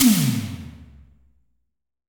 Tom_A2.wav